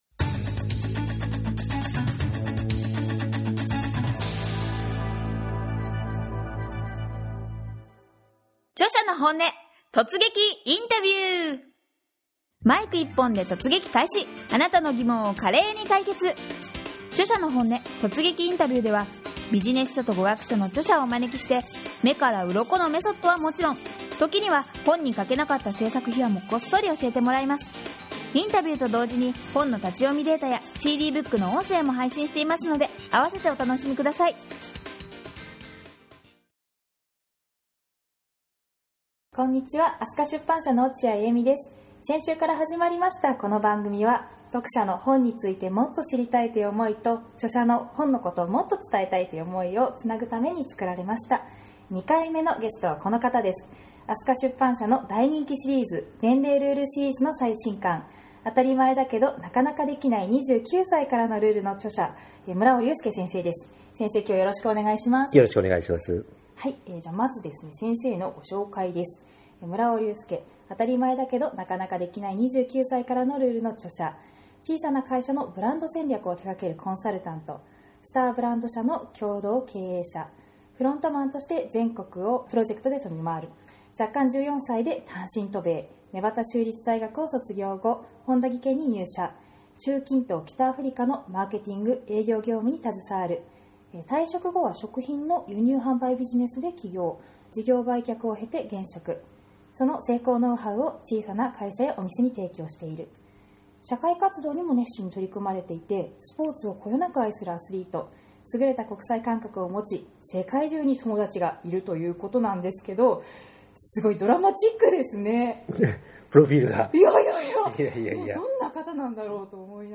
すべての２９歳に、そして自分探しに迷うあなたに届けたいインタビューです！